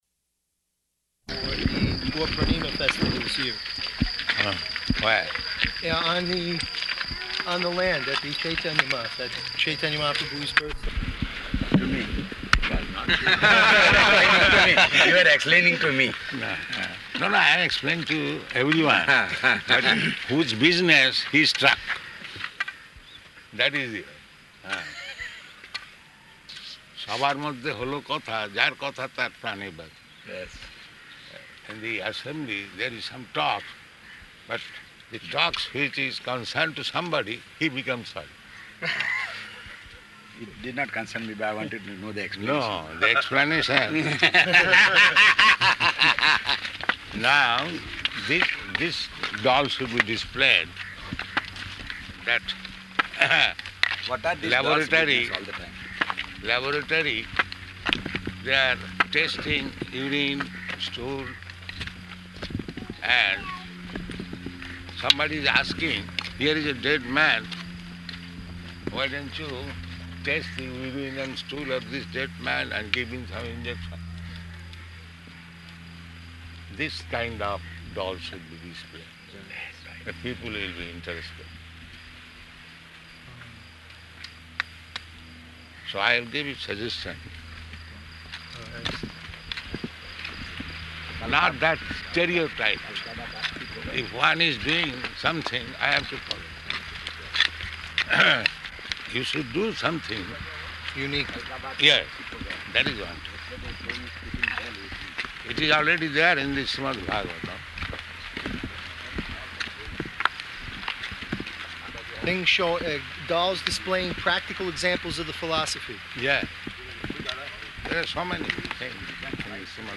-- Type: Walk Dated: November 7th 1975 Location: Bombay Audio file